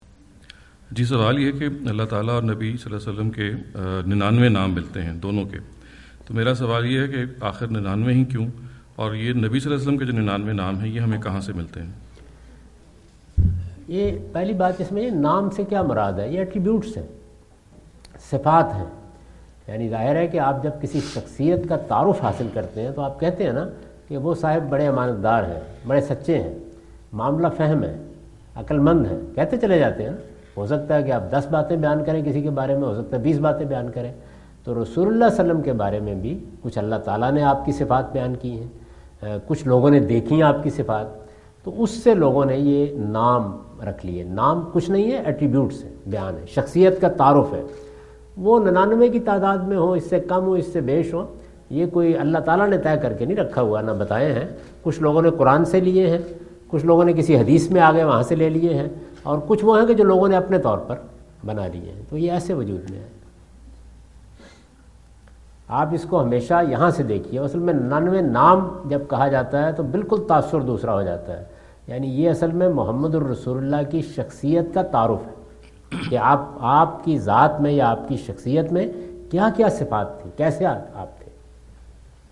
Category: Foreign Tours / USA 2017 / Questions_Answers /
Javed Ahmad Ghamidi answer the question about "Ninety Nine Names of God and His Messenger" During his US visit in Dallas on October 08,2017.